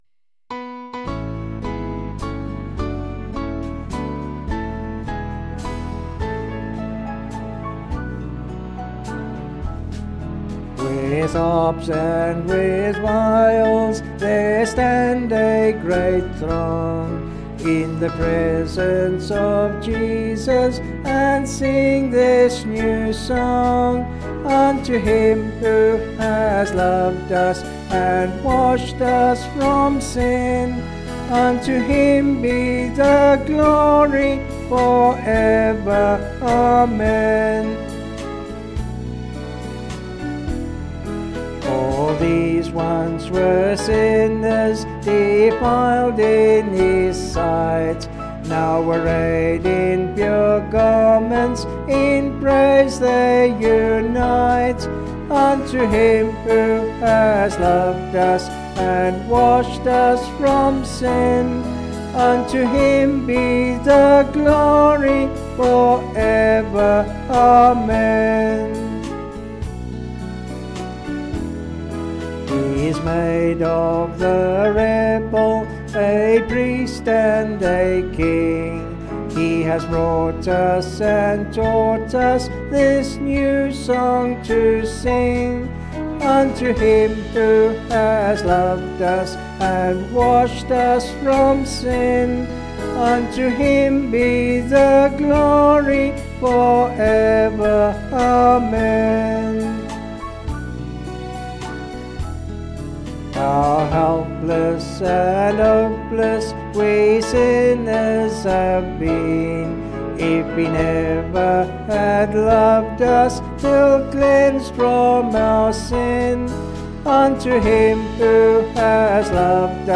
Vocals and Band   263.6kb Sung Lyrics